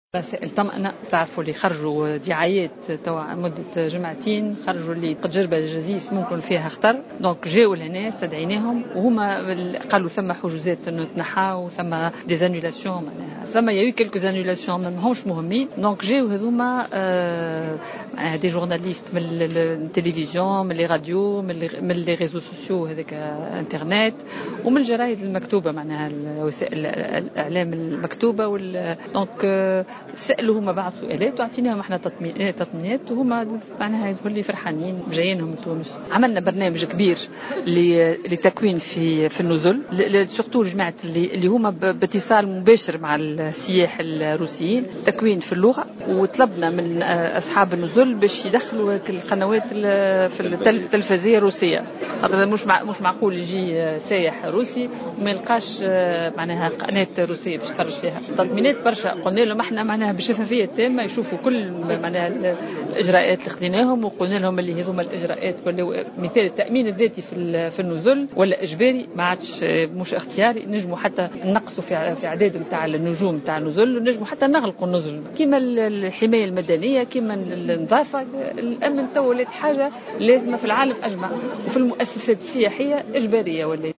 وأكدت الوزيرة في تصريح لمراسلة "الجوهرة أف أم" أن الهدف من هذه الندوة هو بعث رسائل طمأنة إلى السياح الروس، خاصة بعد الأخبار الأخيرة التي تم ترويجها بخصوص وجود تهديدات روسية من عمليات إرهابية تستهدف السياح الروس في تونس.